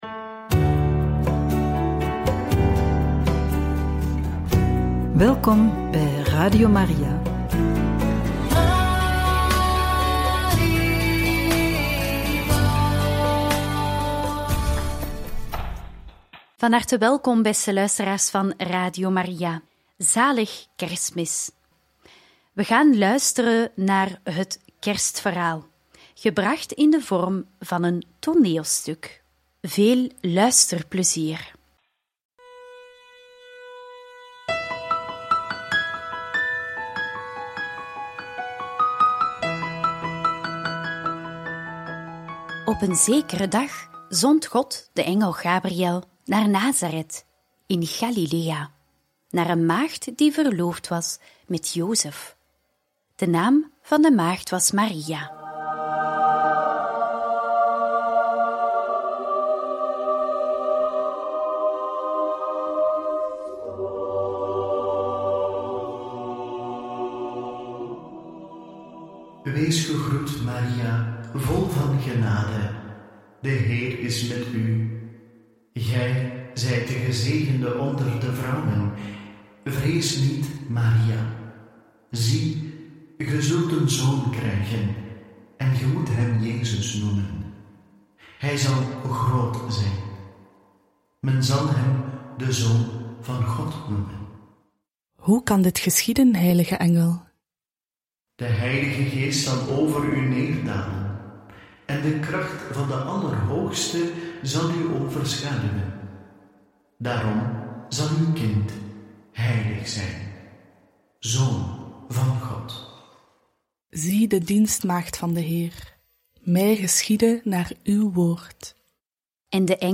Het Kerstverhaal… verteld door de medewerkers van Radio Maria!